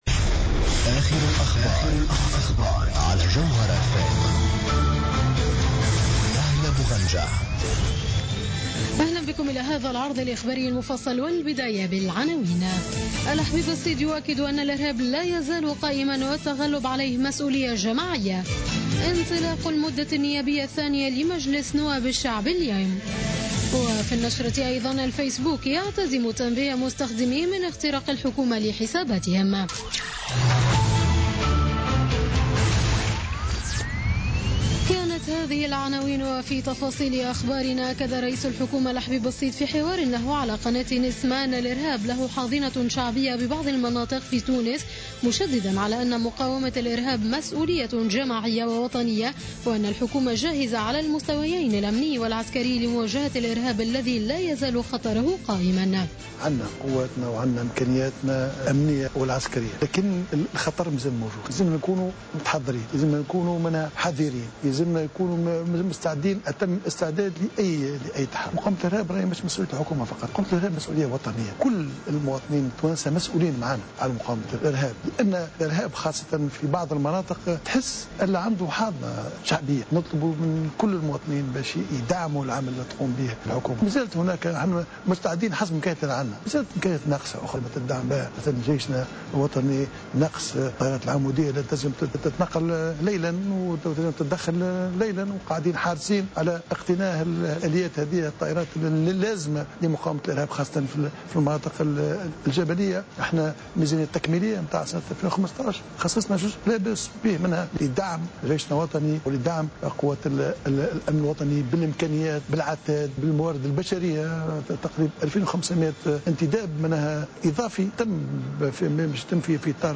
نشرة أخبار منتصف الليل ليوم الثلاثاء 20 أكتوبر 2015